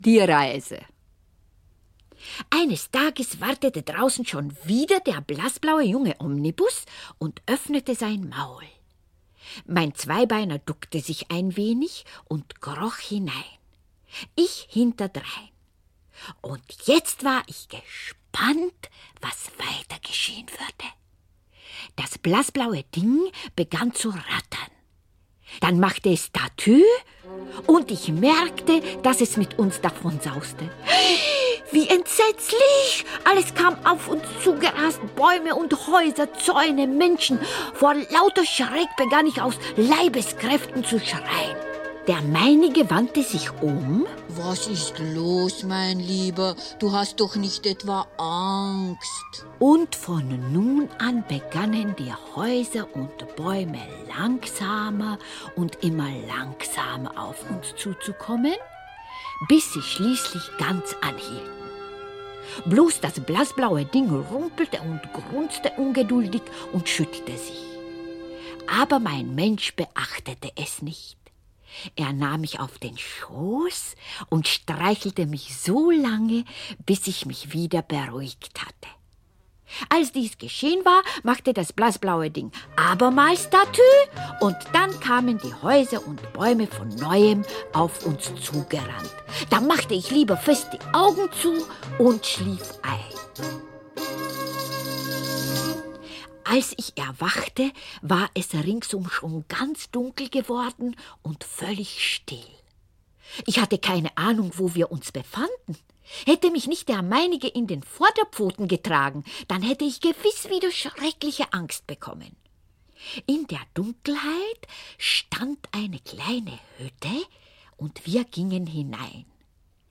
Audiokniha Kater Schnurr mit den blauen Augen - obsahuje příběh v němčině. Hovoří Zdeňka Procházková.